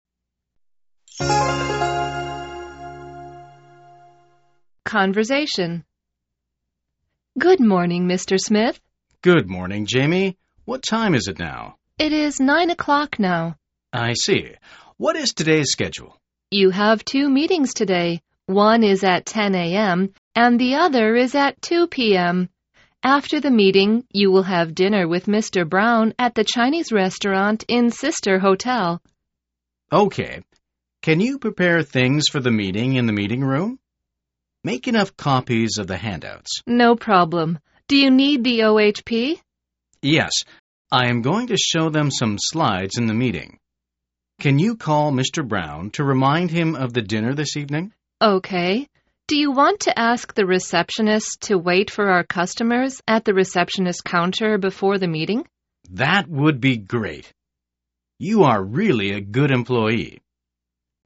口语会话：